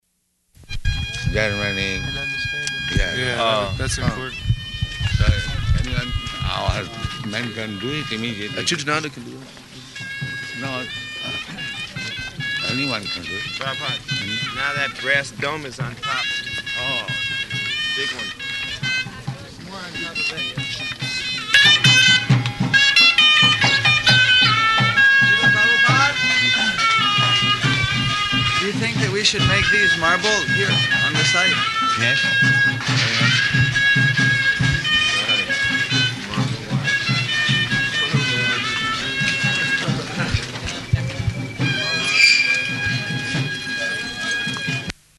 Type: Walk
Location: Māyāpur